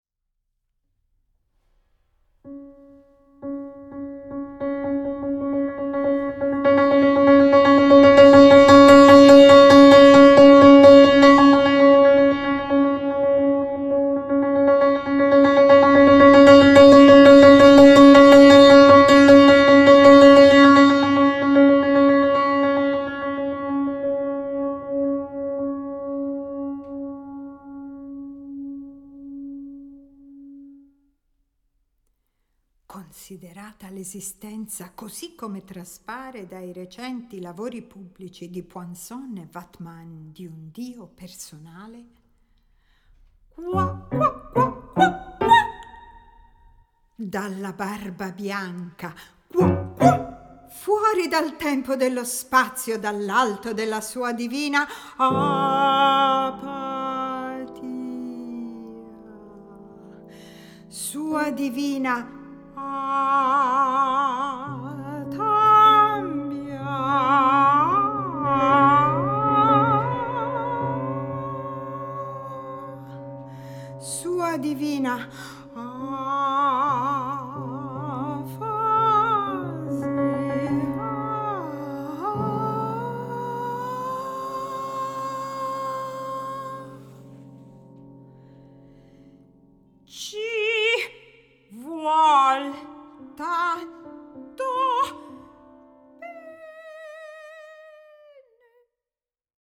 per voce e pianoforte